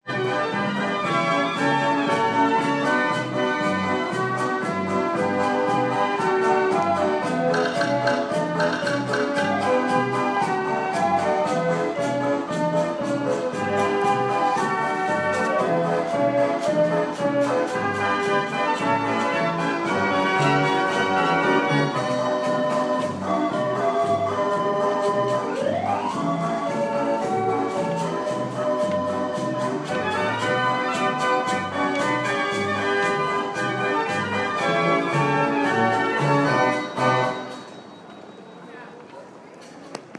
Street organ